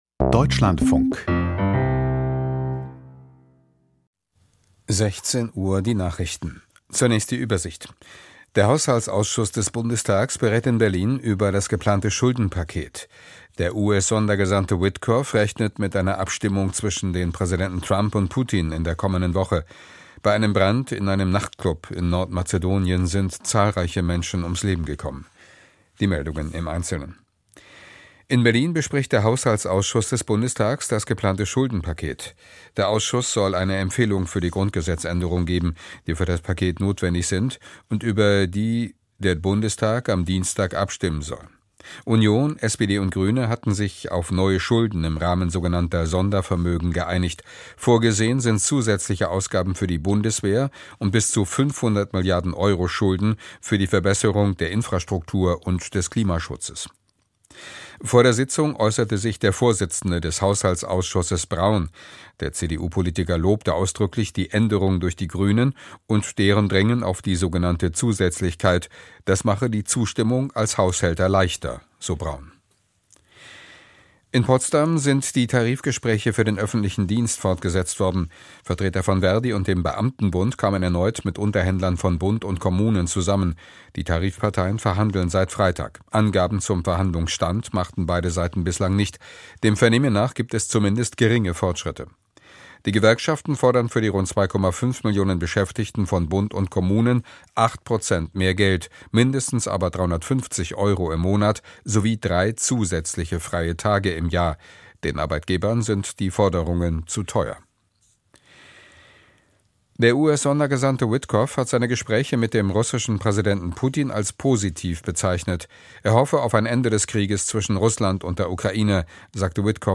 Die Deutschlandfunk-Nachrichten vom 16.03.2025, 16:00 Uhr